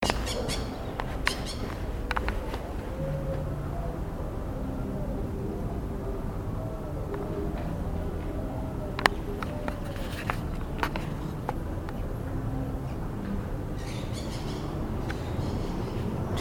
Chiripepé Cabeza Verde (Pyrrhura frontalis)
Nombre en inglés: Maroon-bellied Parakeet
Condición: Silvestre
Certeza: Observada, Vocalización Grabada